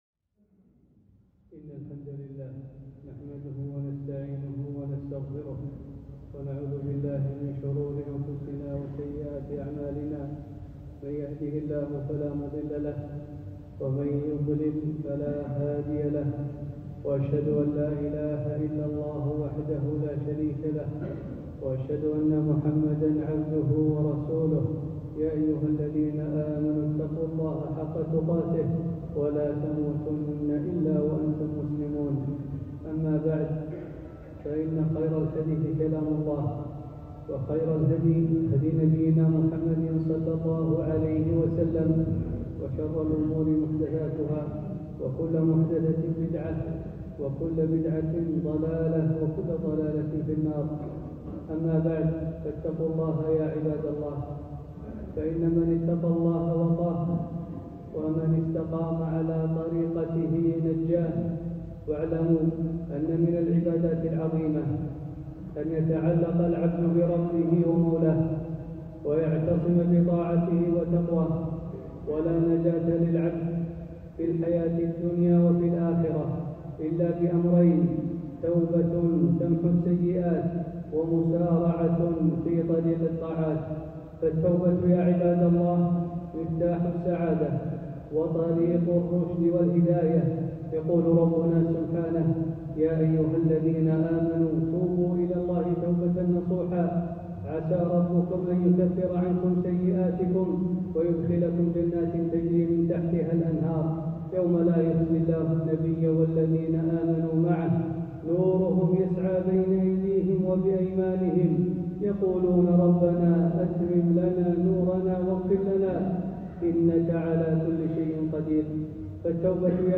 خطبة - فضل التوبة